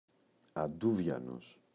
αντούβιανος [a’nduvʝanos] – ΔΠΗ